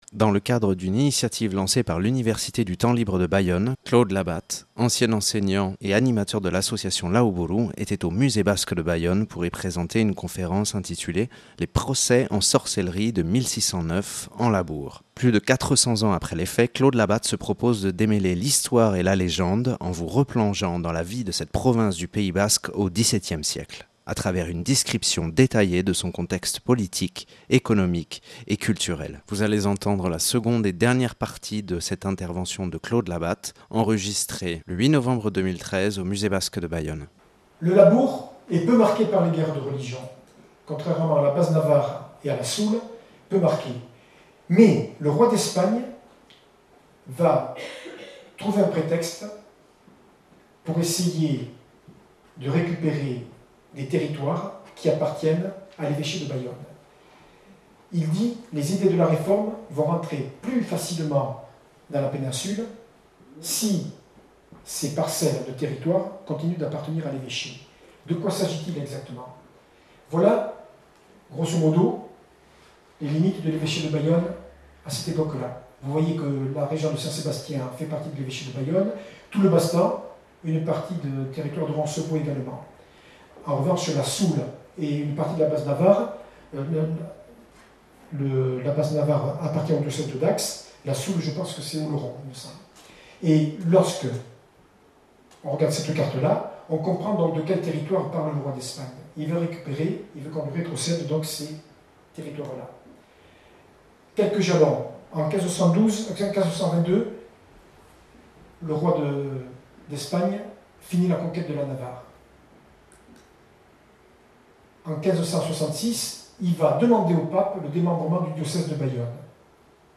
Enregistrée au Musée Basque et de l’histoire de Bayonne le 08/11/2013.